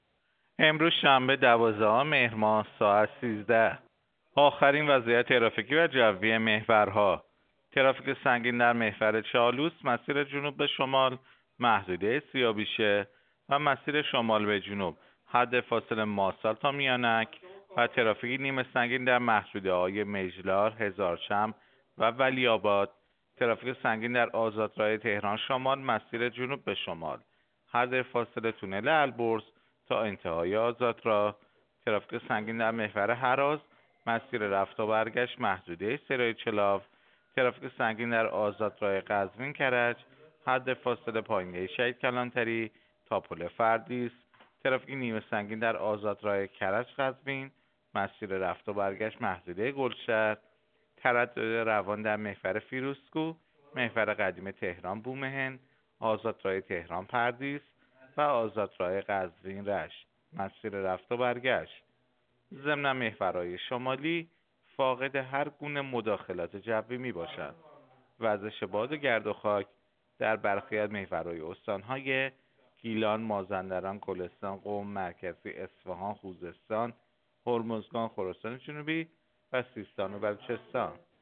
گزارش رادیو اینترنتی از آخرین وضعیت ترافیکی جاده‌ها ساعت ۱۳ دوازدهم مهر؛